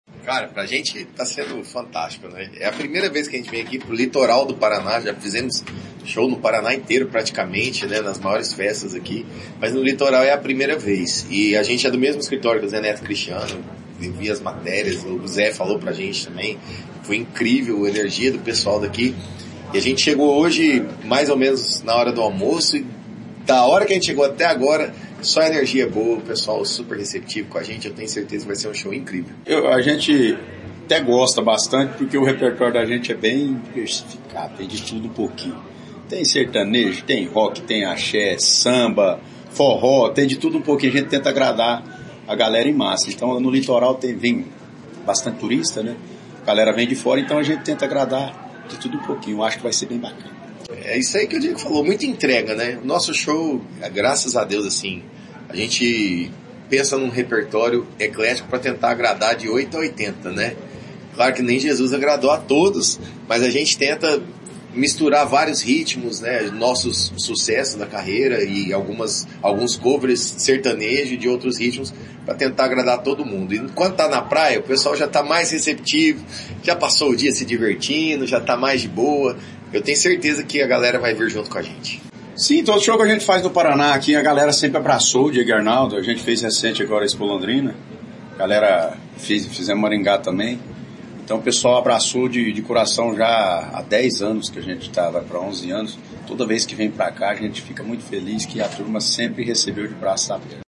Sonora da dupla Diego & Arnaldo sobre a apresentação no Verão Maior Paraná